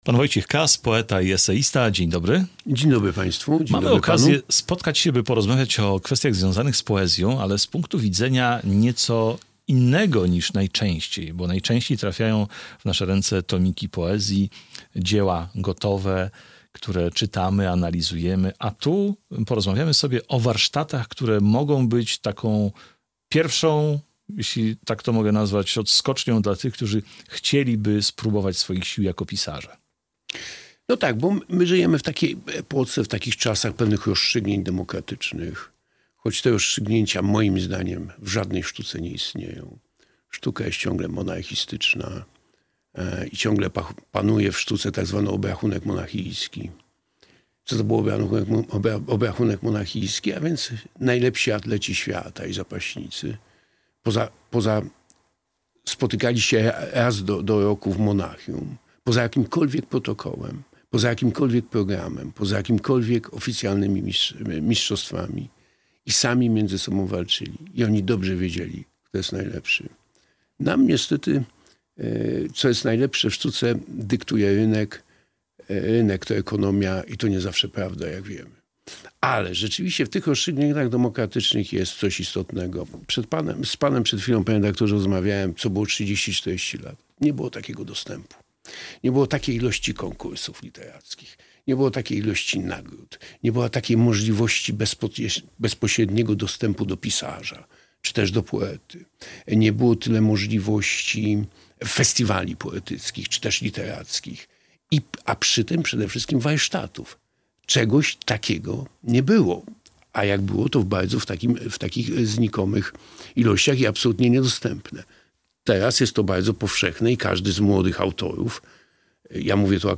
poeta i eseista